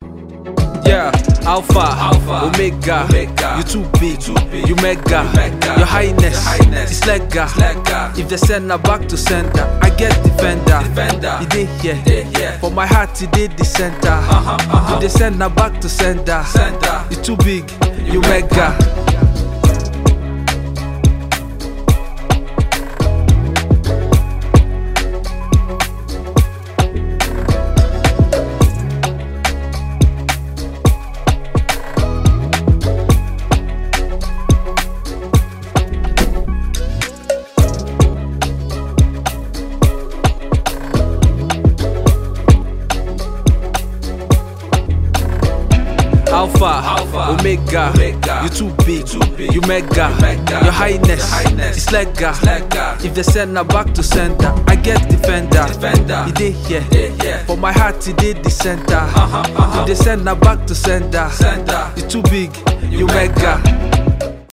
Afro beatmusic
(Open verse)